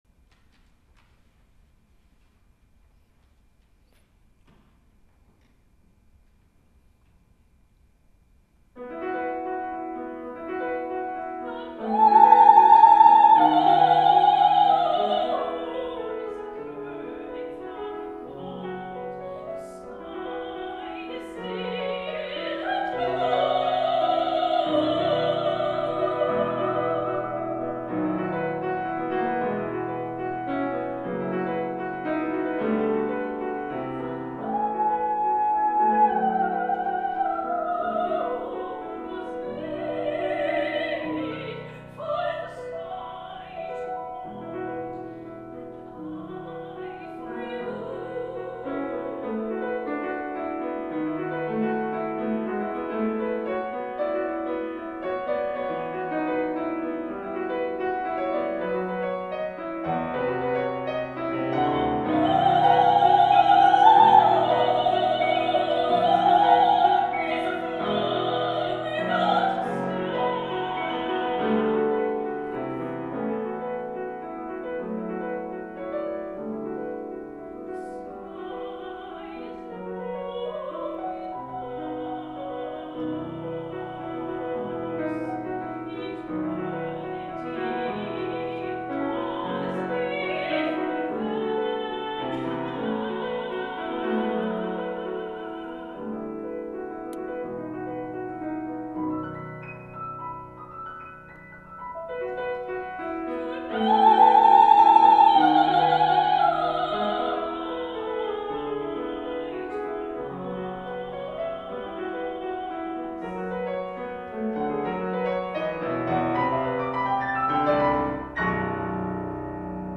Voice and Piano (High and Medium version)